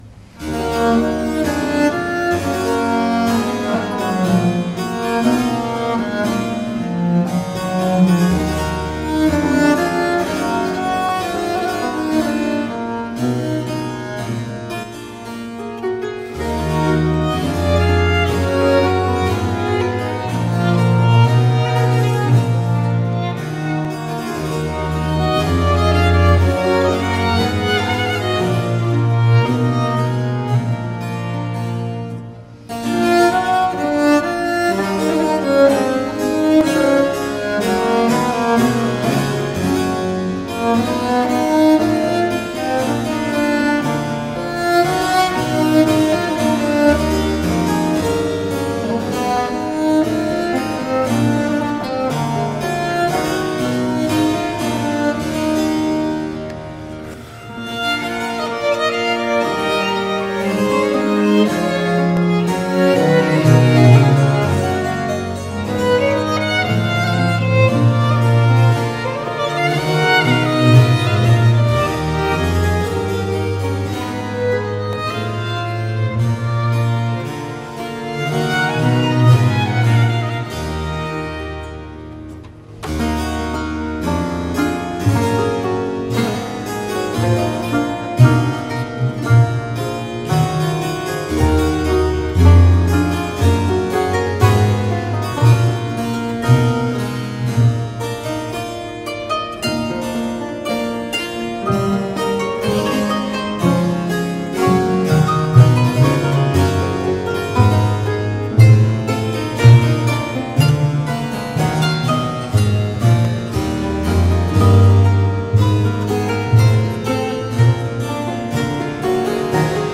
BIBER (Heinrich Ignaz Franz), Arien a quatre en mi m, 04 andante - VERMEER, le geographe.mp3